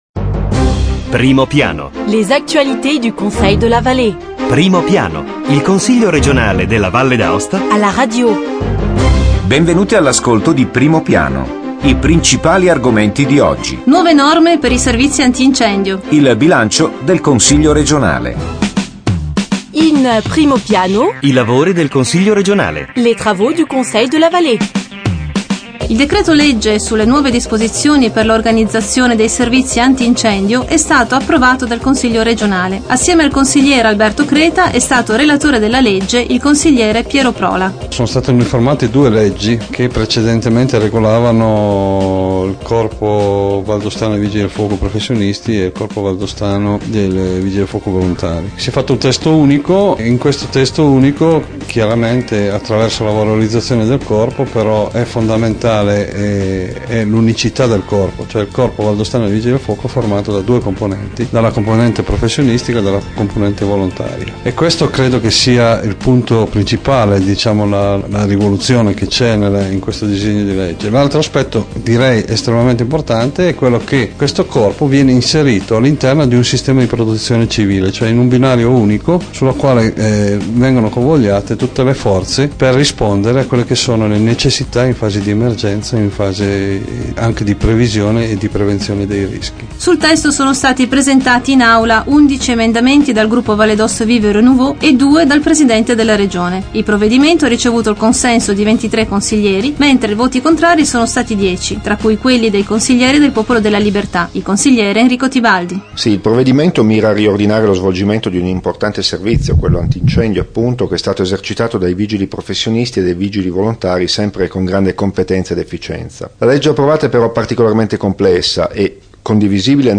Evénements et anniversaires Documents liés De 10 novembre 2009 à 17 novembre 2009 Primo piano Le Conseil r�gional � la radio: approfondissement hebdomadaire sur l'activit� politique, institutionnelle et culturelle de l'assembl�e l�gislative. Voici les th�mes de la nouvelle transmission: - S�ance du Conseil de la Vall�e: nouvelle loi sur les services anti-incendies. Interviews au Conseillers Piero Prola et Enrico Tibaldi; - Approuv� le budget du Conseil r�gional pour l'ann�e 2010;